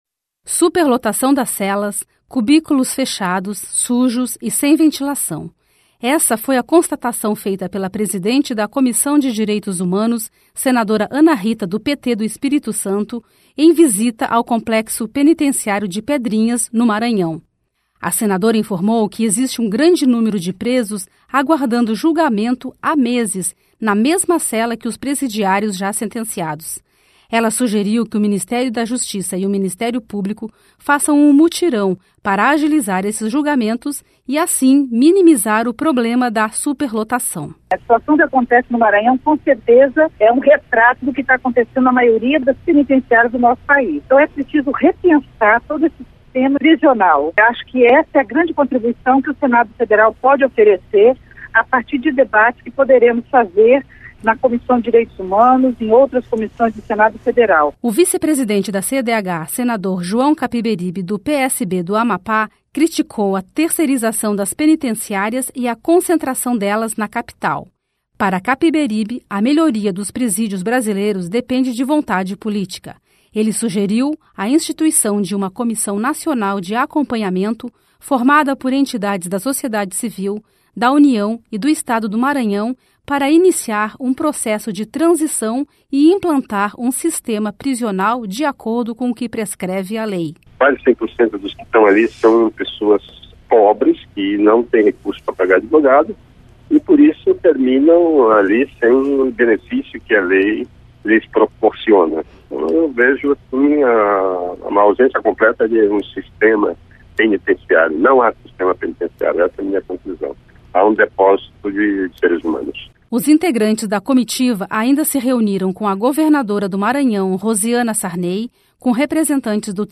(Repórter) Superlotação das celas, cubículos fechados, sujos e sem ventilação.